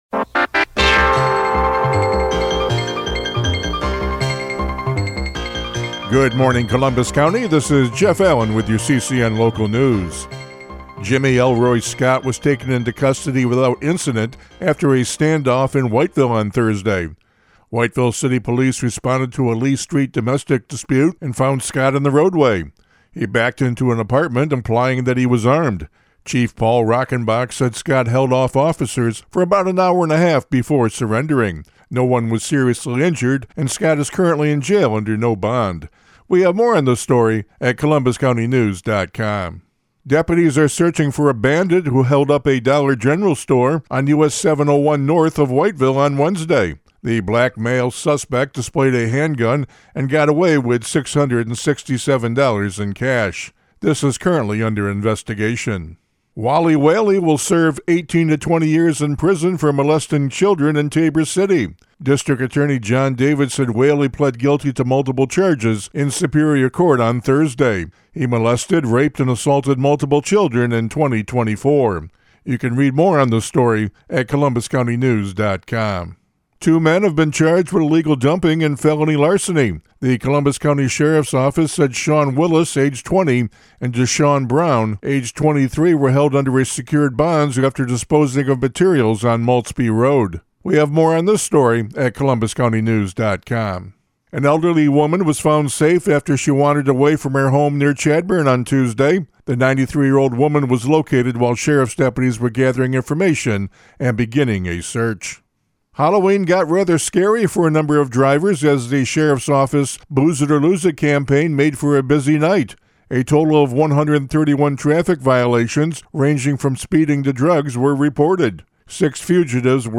CCN Radio News — Morning Report for November 7, 2025